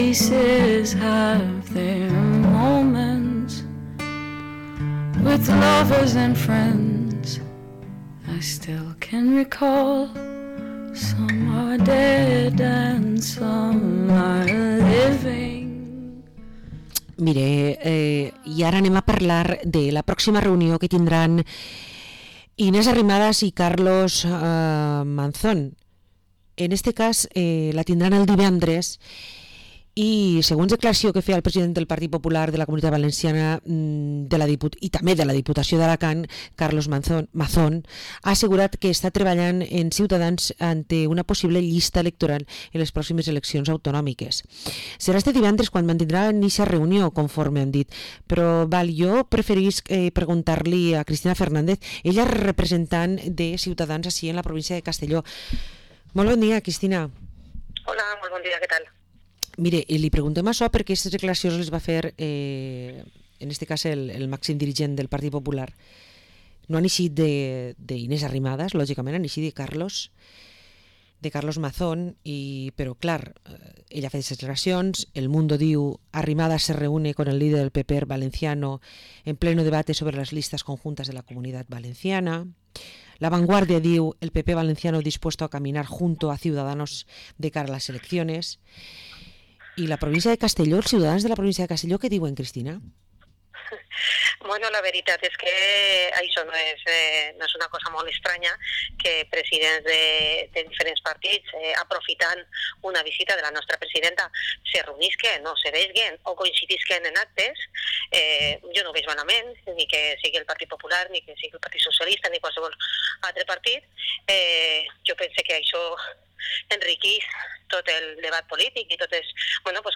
Entrevista a la portavoz de Ciudadanos en la Diputación de Castellón, Cristina Fernández